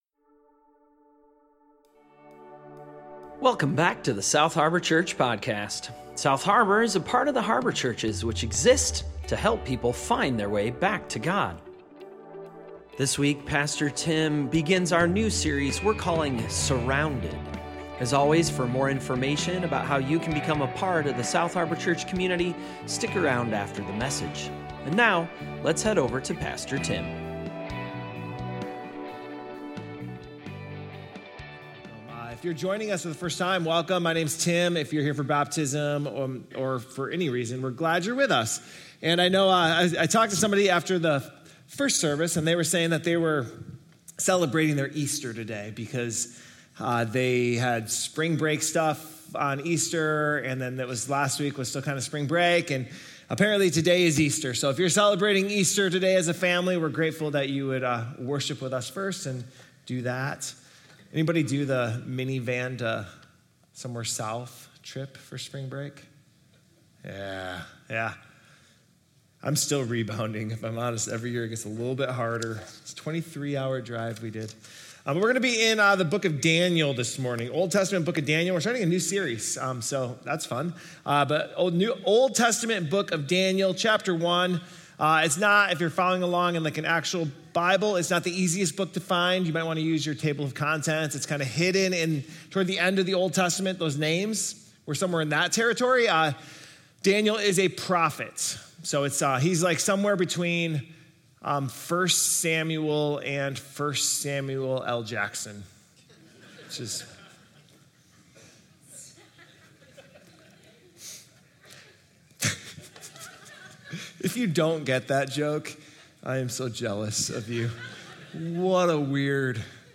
South Harbor Sermons